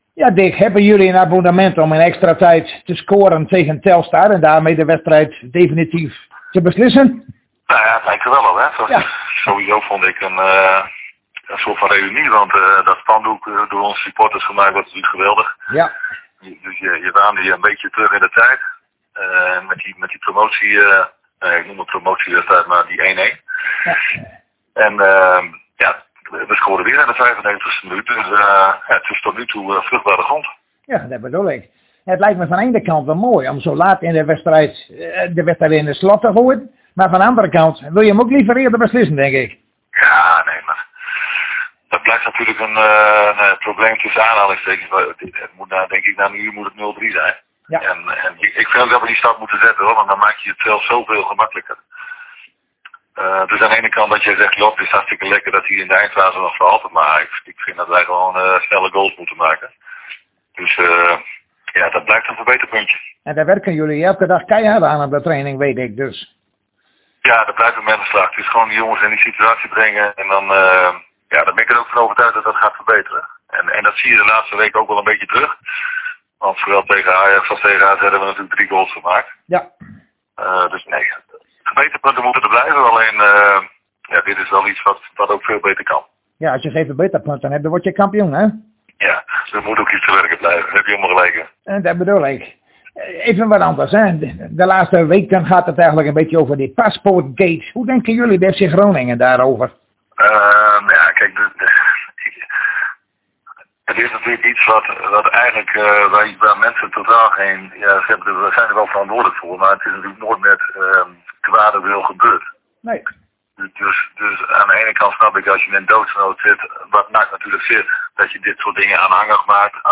Zojuist spraken wij weer met trainer Dick Lukkien over de wedstrijd van morgen tegen GA Eagles.